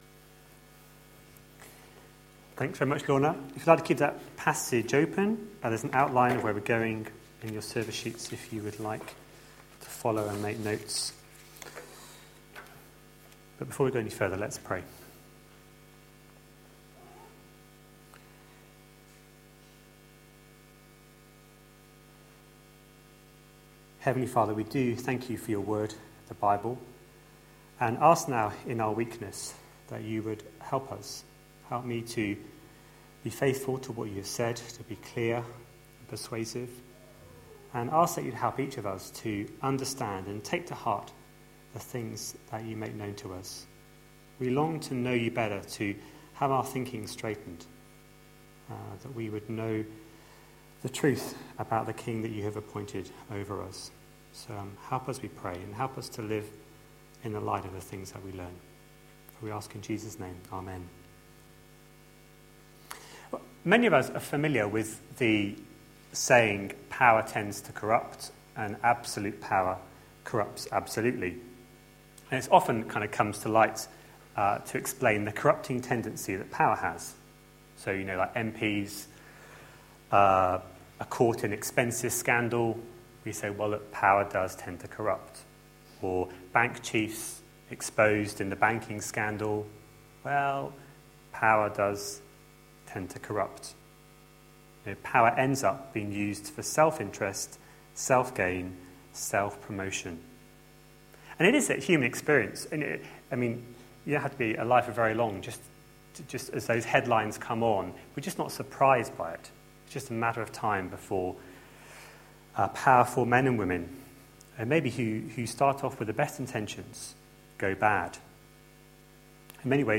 A sermon preached on 18th August, 2013, as part of our God's King? series.